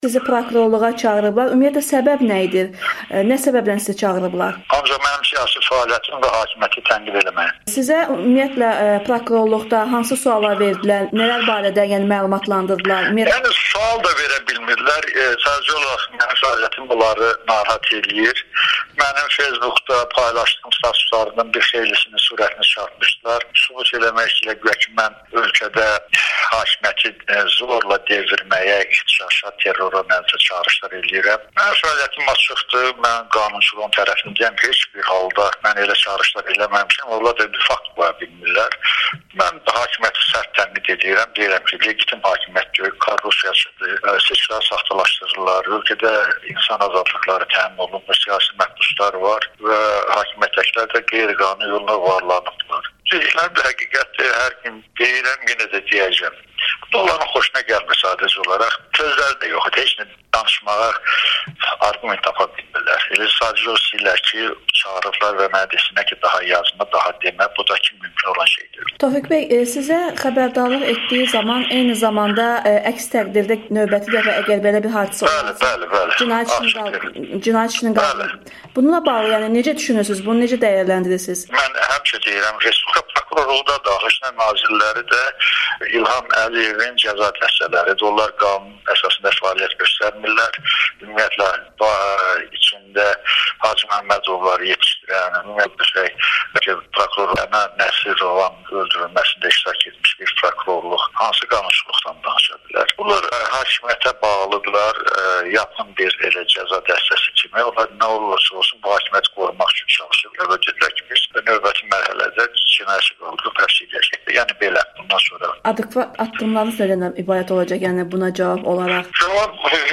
Tofiq Yaqublunun Amerikanın Səsinə müsahibəsi